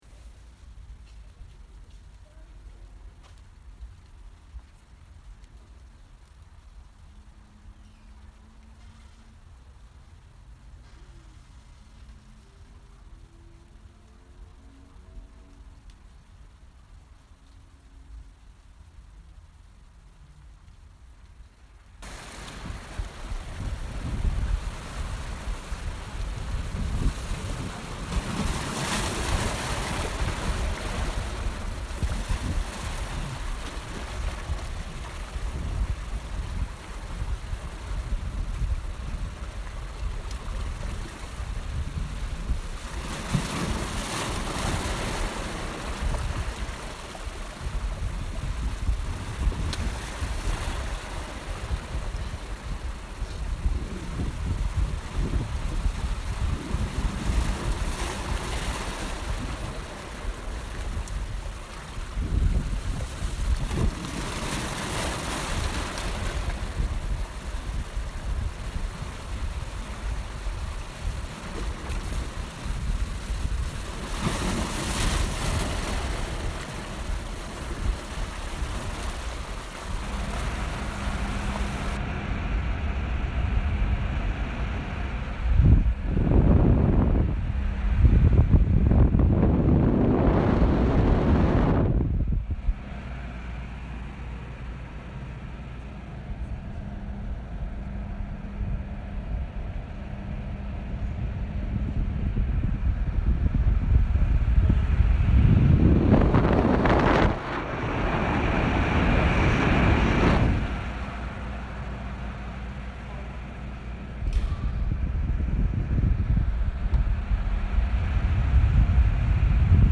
Wearing a red evening gown atop a white sheet, I perform an improvised dance to a sound piece of water and air (listen below).
The sound of wind pins my body to the ground as I struggle to stand against its mighty blows.
air-water-1.mp3